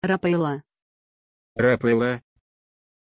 Pronunția numelui Raphaëlla
pronunciation-raphaëlla-ru.mp3